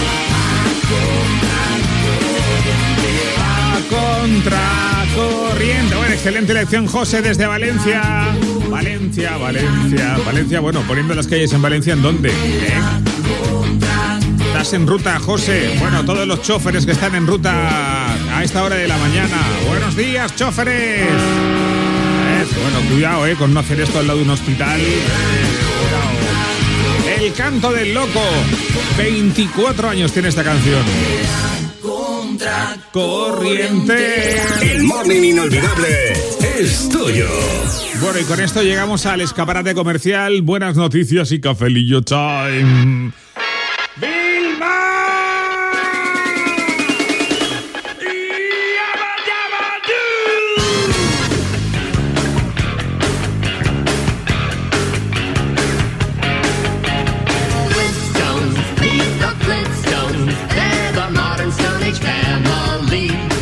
Salutació a un oïdor i als xòfers, indicatiu del programa,